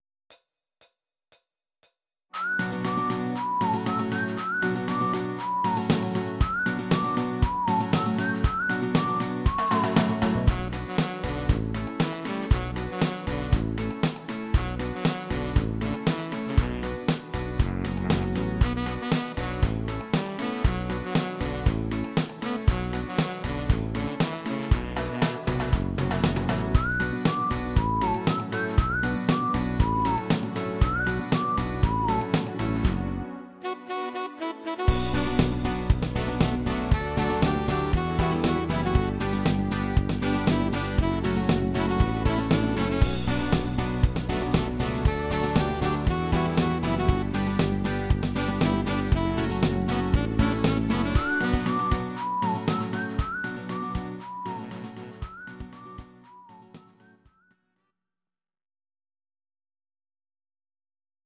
Audio Recordings based on Midi-files
Pop, Ital/French/Span, 2000s